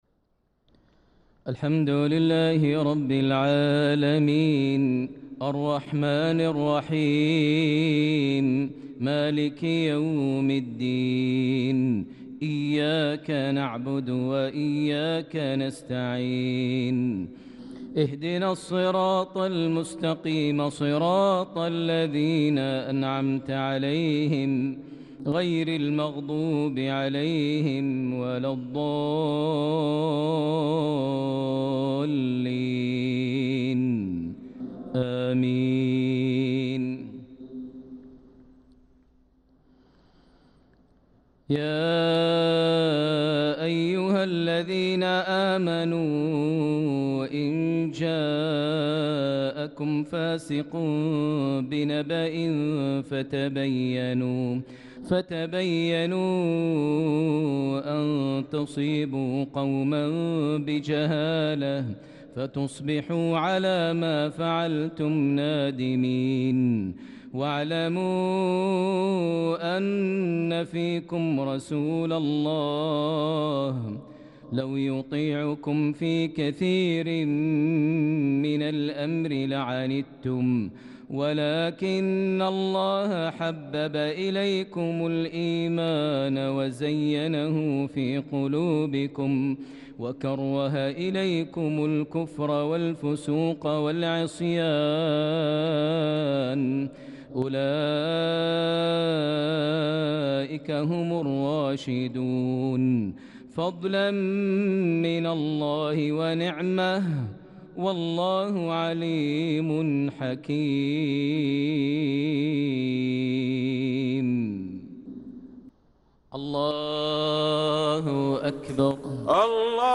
صلاة العشاء للقارئ ماهر المعيقلي 22 ذو الحجة 1445 هـ
تِلَاوَات الْحَرَمَيْن .